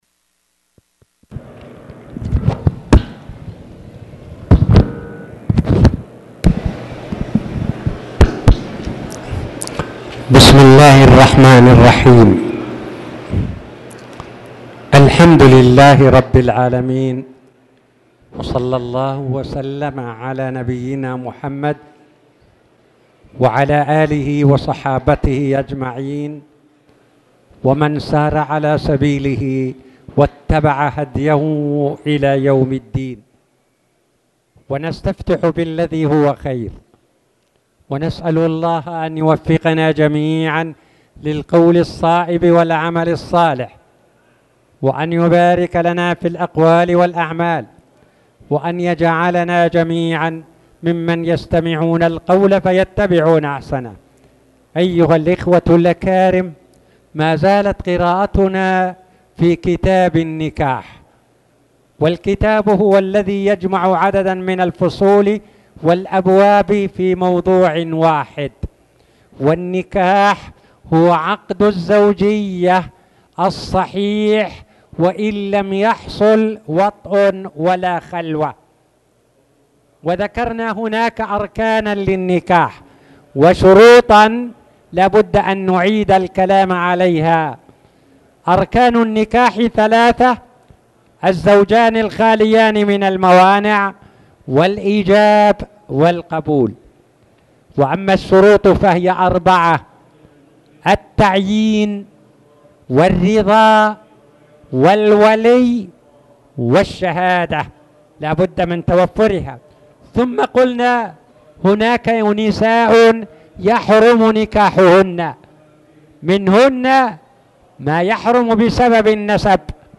تاريخ النشر ١٢ ربيع الأول ١٤٣٨ هـ المكان: المسجد الحرام الشيخ